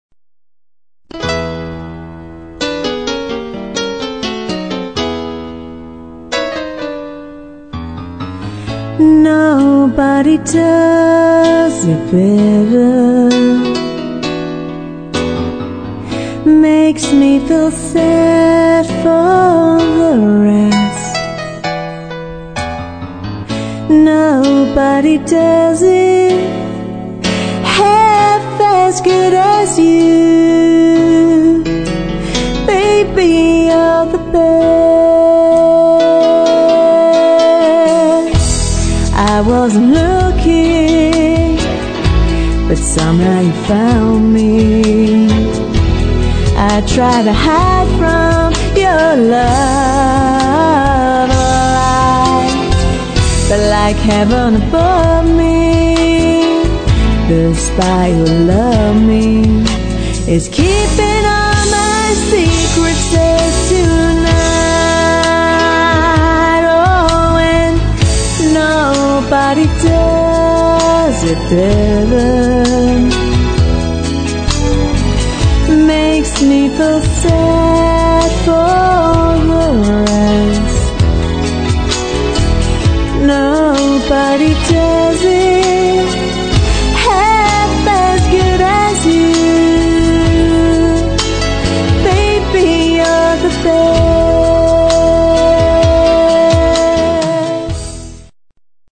• Six-piece band
• Two female lead vocalists